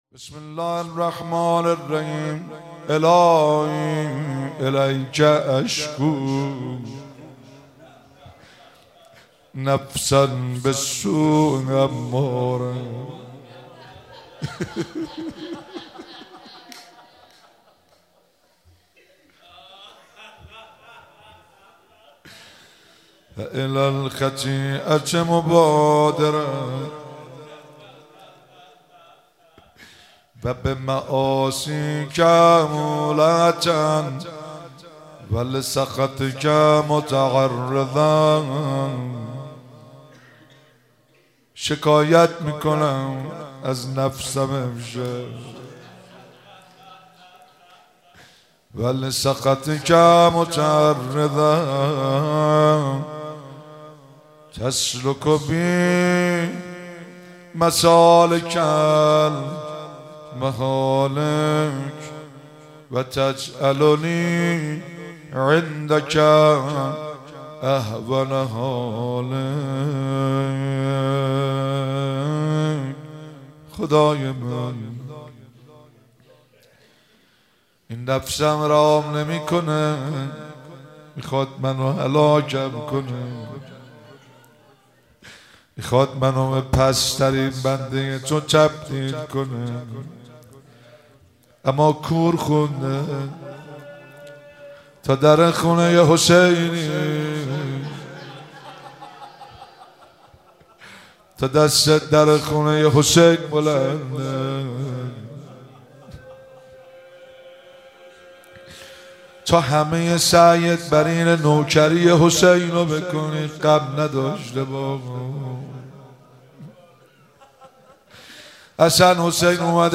مراسم مناجات خوانی شب بیست و دوم ماه رمضان 1444